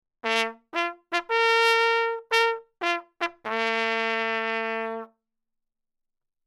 Auf unserer Website bieten wir auch unter anderem traditionelle Jagdsignale zum Anhören an.
Jagdhorn
Jagdleitersignale: